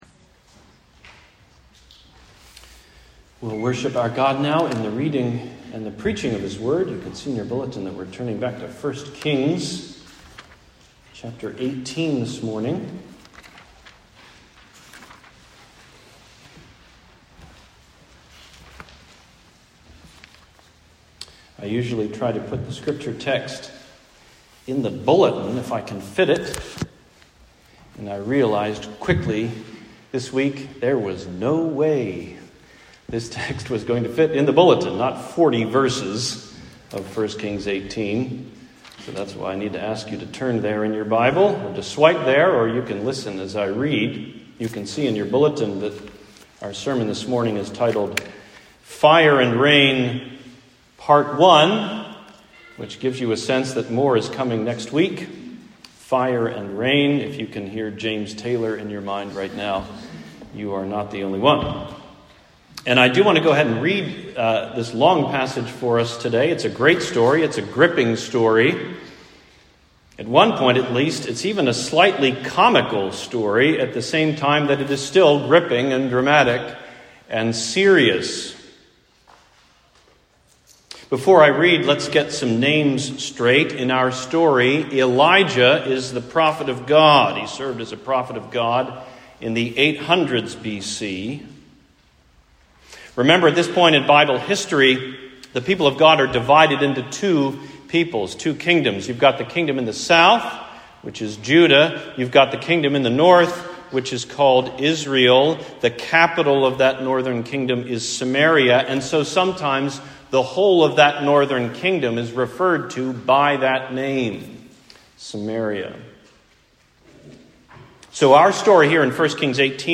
Fire and Rain (Part 1): Sermon on 1 Kings 18:1-40